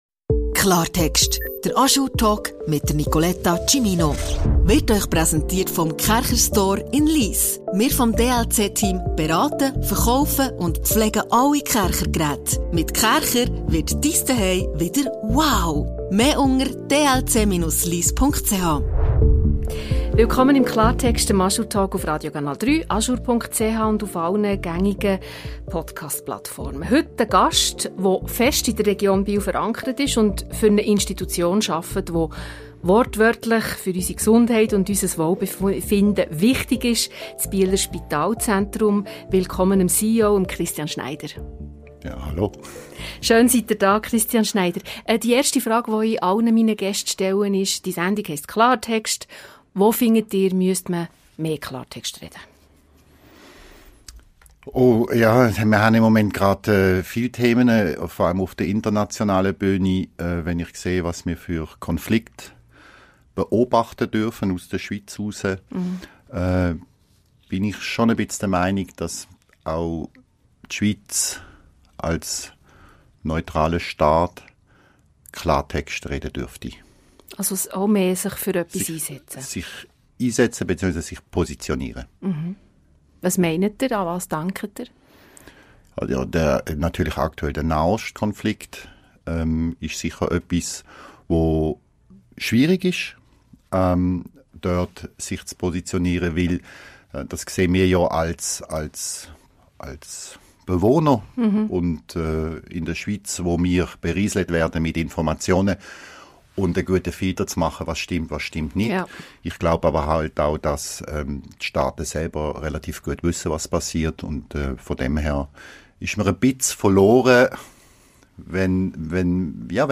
«Es gibt immer Lösungen» ~ Klartext - der ajour-Talk Podcast